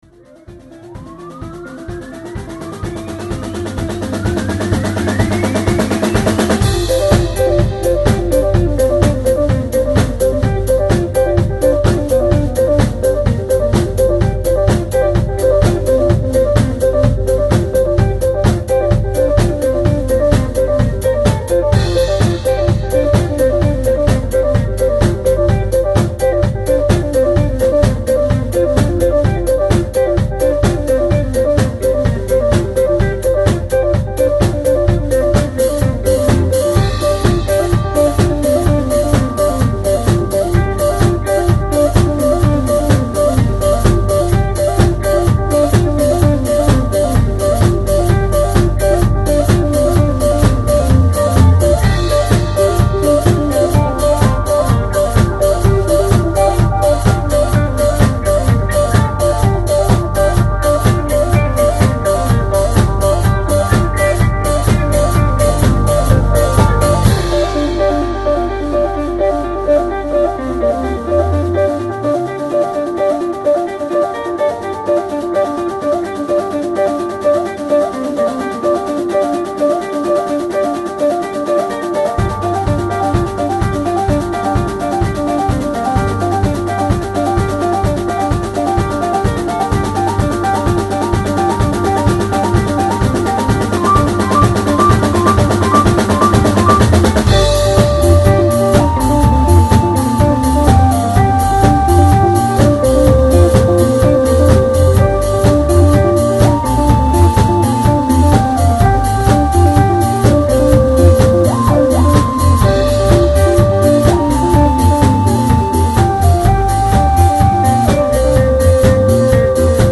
空間を心地良くあっためてく。